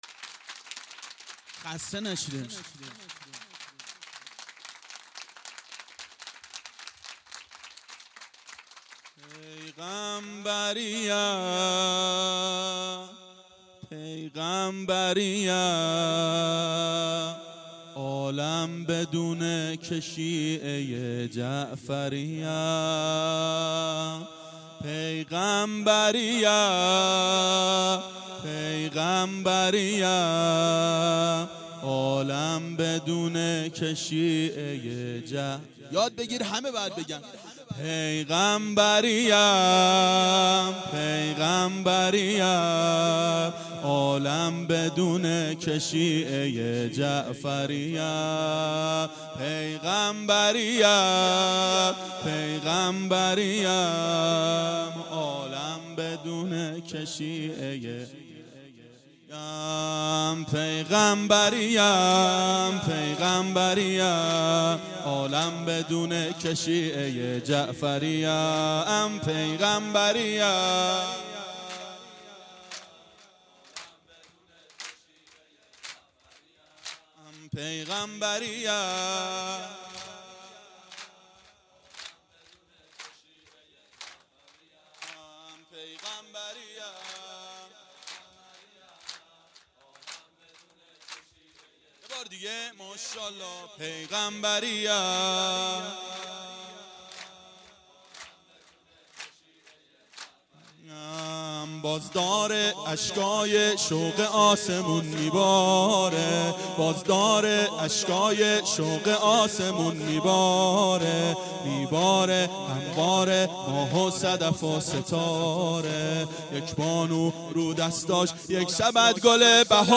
پیغمبریم / سرود
• میلاد پیامبر و امام صادق علیهماالسلام 92 هیأت عاشقان اباالفضل علیه السلام منارجنبان